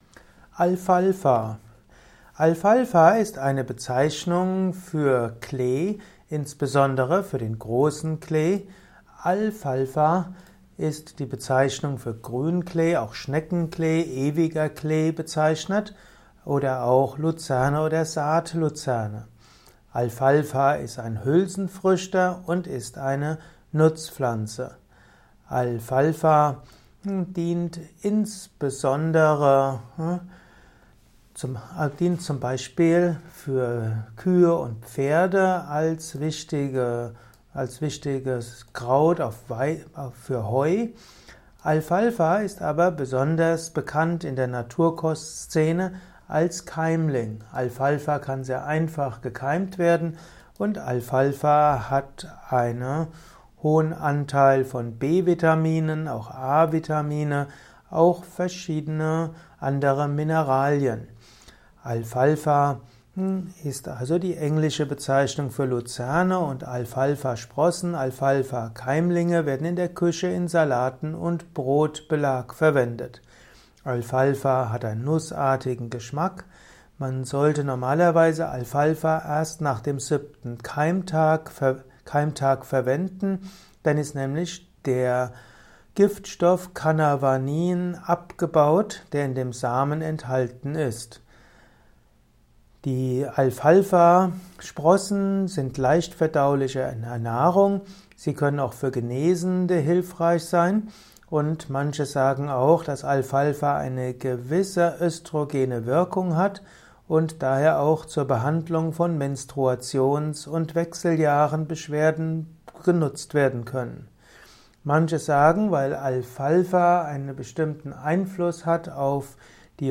Alfalfa - Simple und Komplexe Informationen zum Thema Alfalfa in diesem Kurzvortrag. Höre etwas über Alfalfa vom einem Standpunkt von Yoga und Yogatherapie aus.